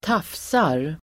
Ladda ner uttalet
tafsa verb, paw , handle Grammatikkommentar: A & på B Uttal: [²t'af:sar] Böjningar: tafsade, tafsat, tafsa, tafsar Synonymer: kladda, pilla Definition: (på ett obehagligt sätt) fingra (på en person), kladda
tafsar.mp3